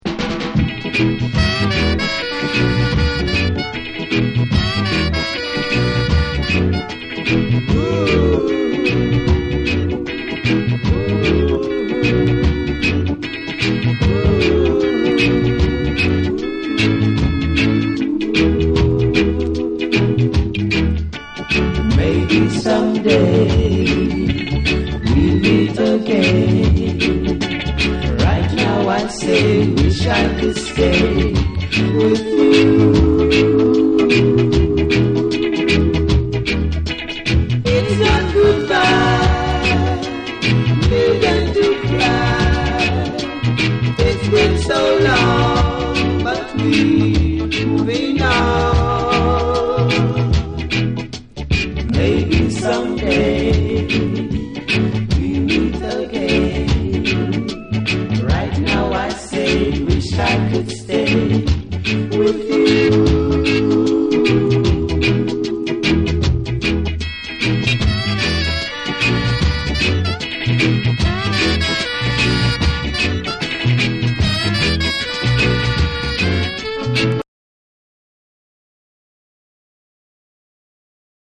※ジャマイカ盤リイシュー（ジャマイカ盤特有のチリノイズが入ります。
REGGAE & DUB